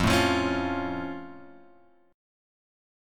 F+ chord {1 0 3 x 2 1} chord
F-Augmented-F-1,0,3,x,2,1.m4a